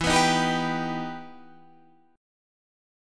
EmM7 chord